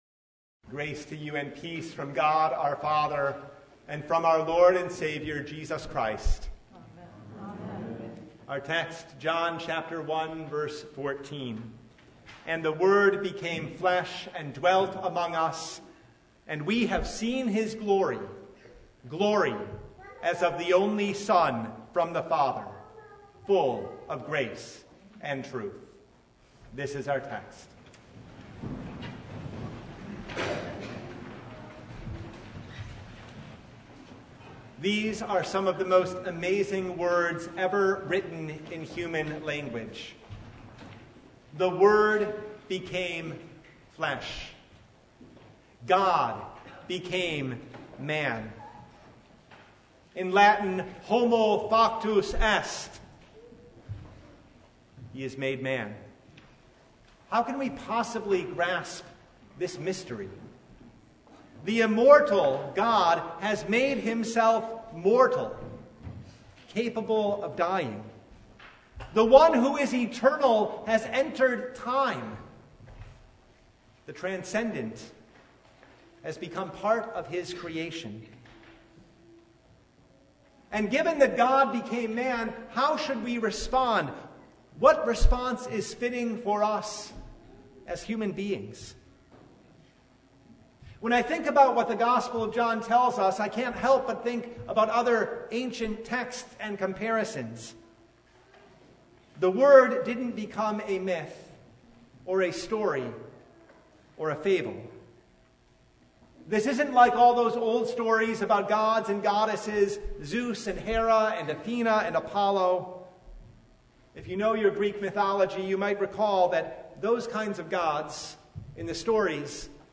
John 1:14 Service Type: Christmas Day Topics: Sermon Only « What Will This Child Be?